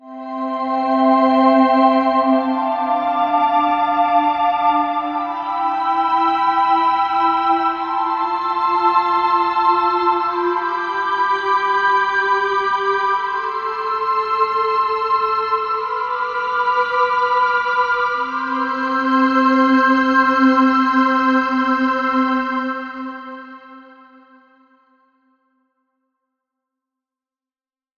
Cinematic Pads fa parte della serie Originals e come suggerisce il nome è una raccolta di Pad dal suono epico, un tipo di preset di cui si è sempre alla ricerca.
Spitfire Audio ha messo insieme 45 suoni di pad processando campioni orchestrali sinfonici che poi sono stati trasformati e warpati per ottenere suoni davvero ricchi.
• Bowed Glass, un’orchestra di vetro che suona in una cattedrale
bowed-glass.wav